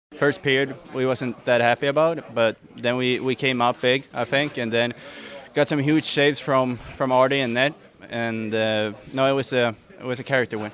Hallander says the Penguins picked up the intensity after falling behind.